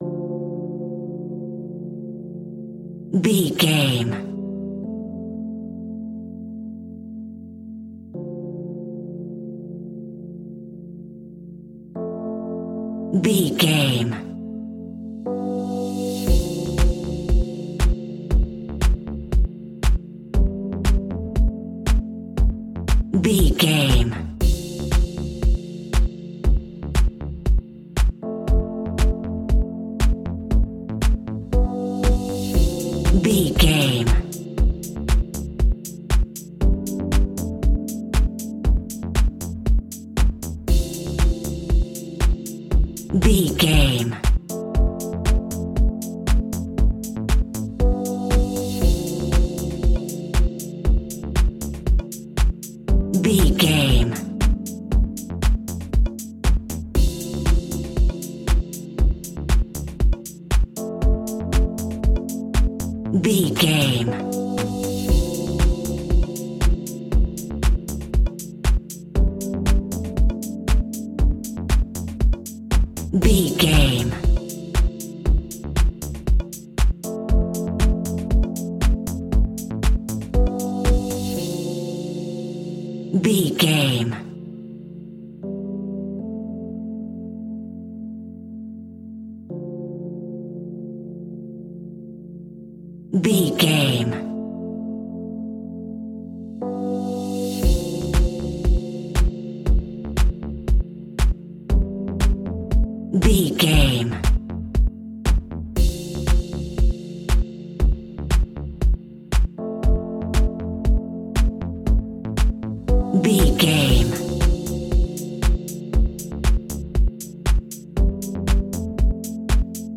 Aeolian/Minor
D
groovy
peaceful
smooth
drum machine
synthesiser
electro house
funky house
synth leads
synth bass